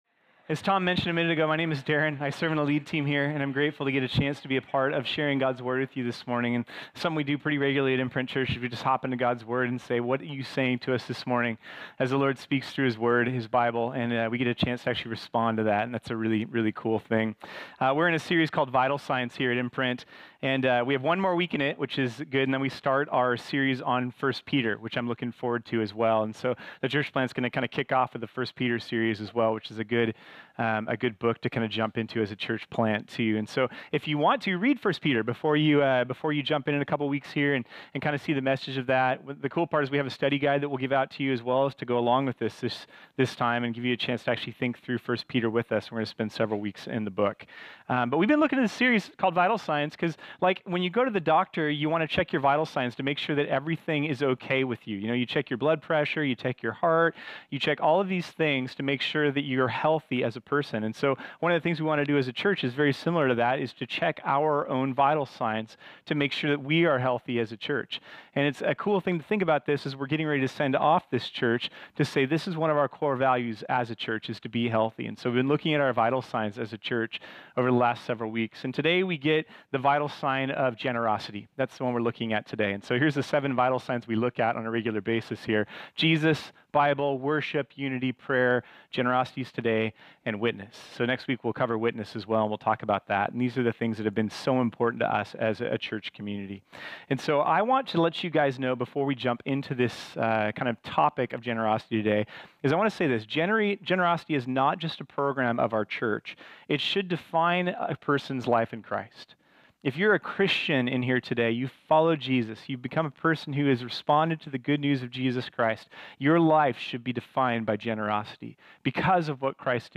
This sermon was originally preached on Sunday, February 27, 2022.